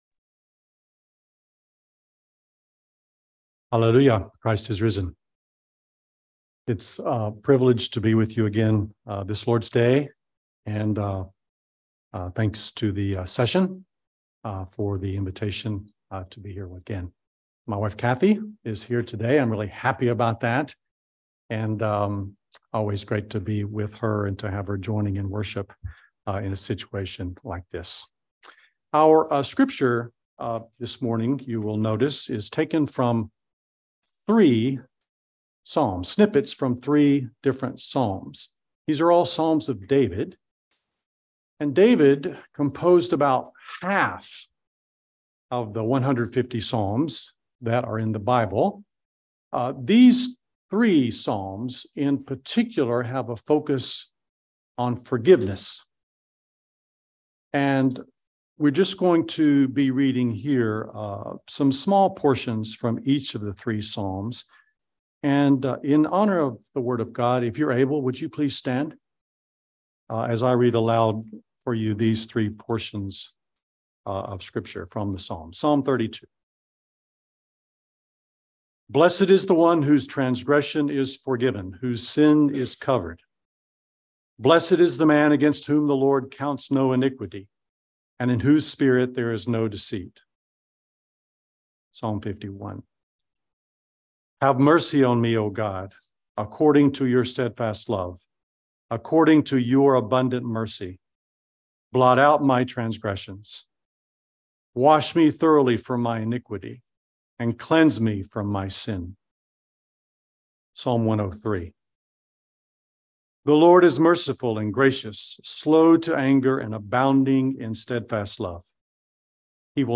by Trinity Presbyterian Church | Aug 7, 2023 | Sermon